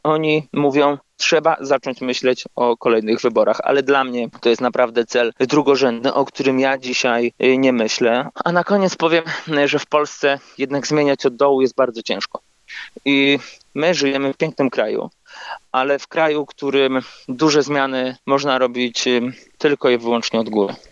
ROZMOWA DNIA